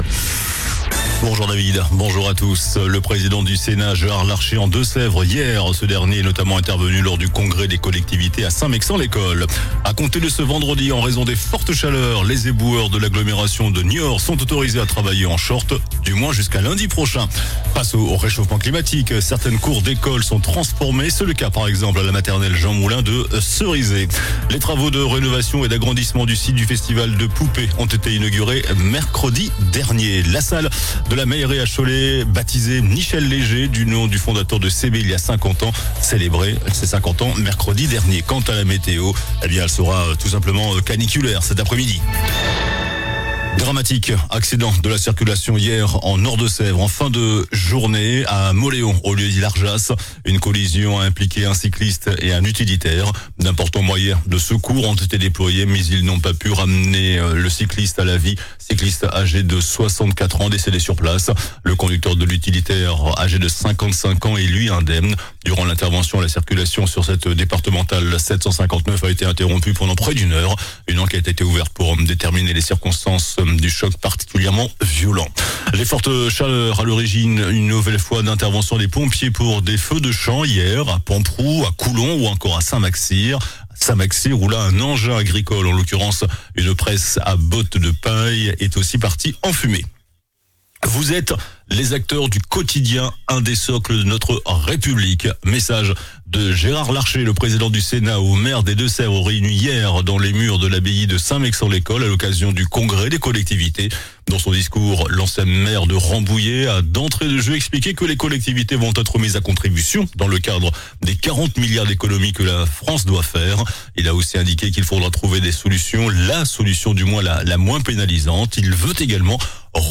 JOURNAL DU VENDREDI 20 JUIN ( MIDI )